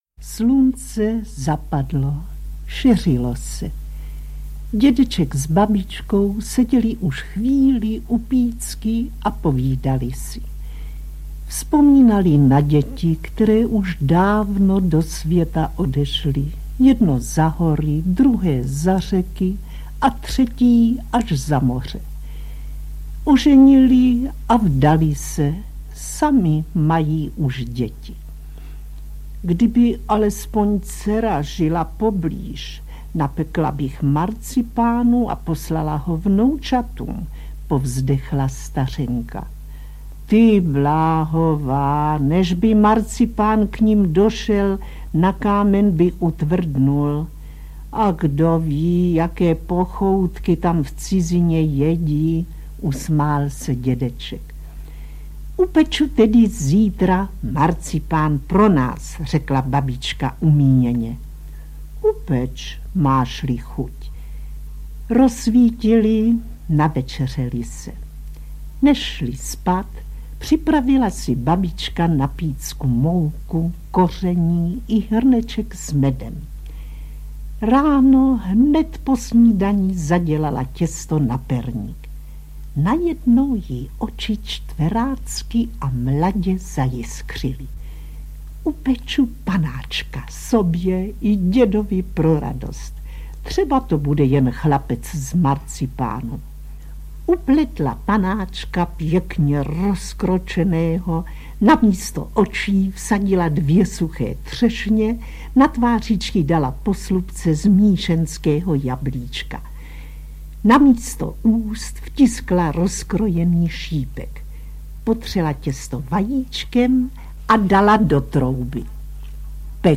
Dvě překrásné pohádky pro děti v nezapomenutelném přednesu herečky Otýlie Beníškové.
Audio kniha
• InterpretOtýlie Beníšková